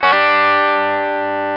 Guitar Bent Th Sound Effect
Download a high-quality guitar bent th sound effect.
guitar-bent-th.mp3